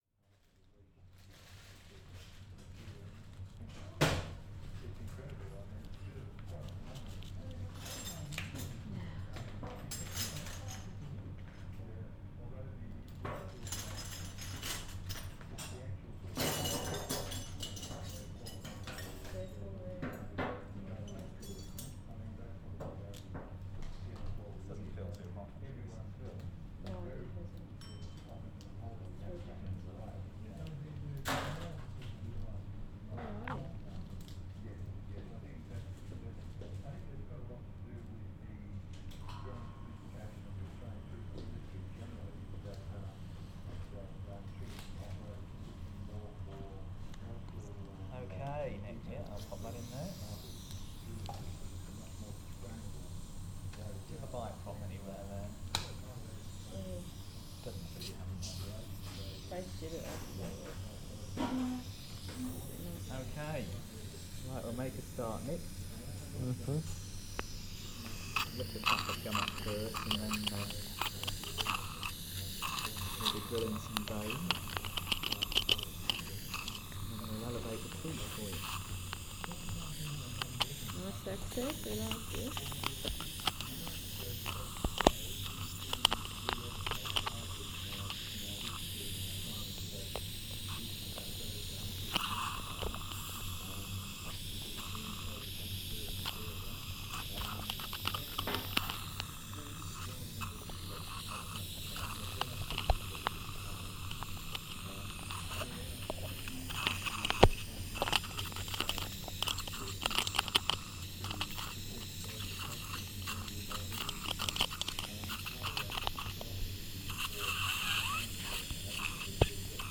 Wisdom tooth extraction (binaural)
The drilling phase of my own wisdom tooth extraction recorded using a pair of binuaral microphones, with another pair of microphones taped to my jawbone to capture sound via bone conduction. This is a 14 minute recording of the operation which occurred in 2003.